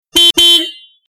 smart_hornbefore.mp3